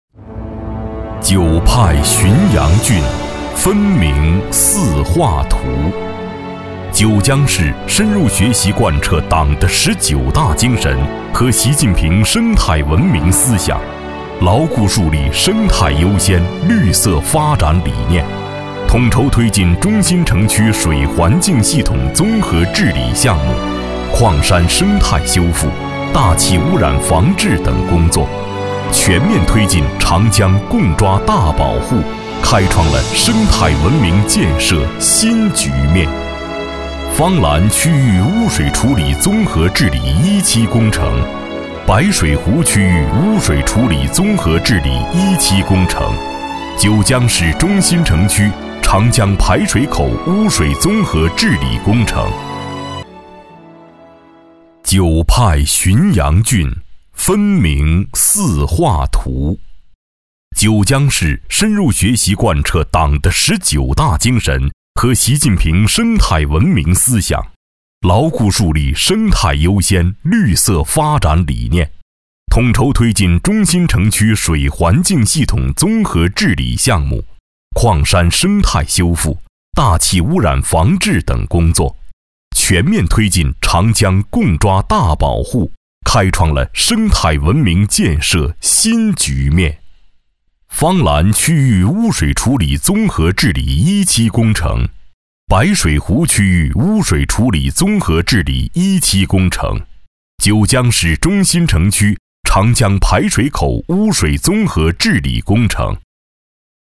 男国463_专题_政府_伟大的奋斗河清水畅风光美_抒情.mp3